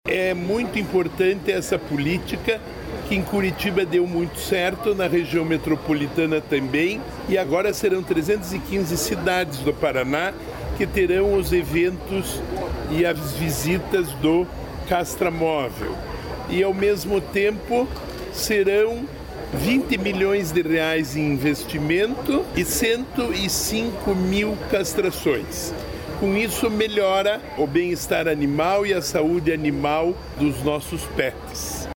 Sonora do secretário do Desenvolvimento Sustentável, Rafael Greca, sobre o 5º ciclo do CastraPet